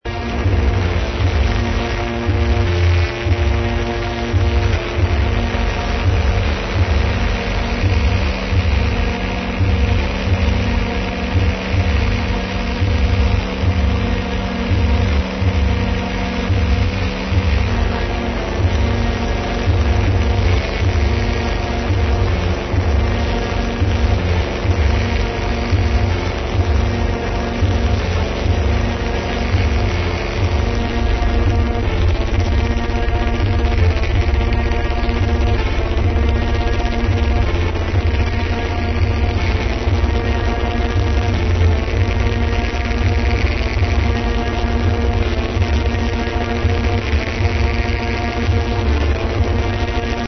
Great monotonous drones & massive sound